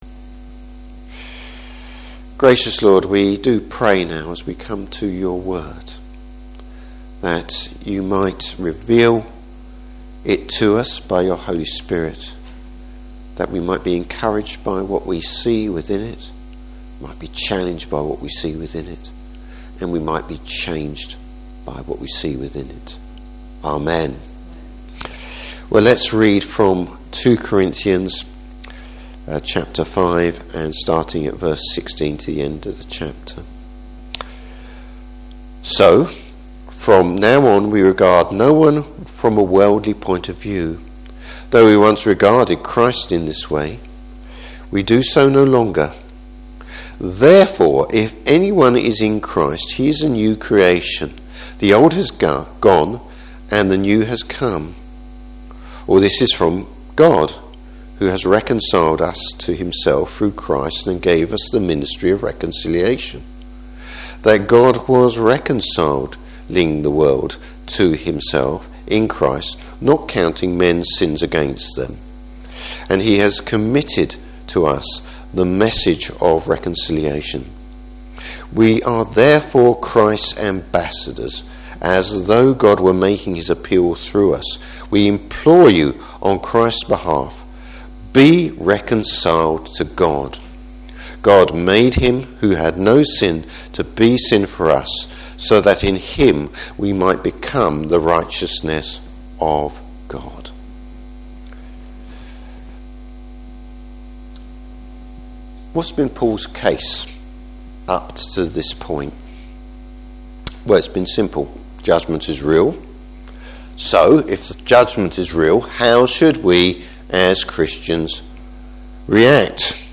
Service Type: Morning Service How can such an unthinkable reconciliation come about?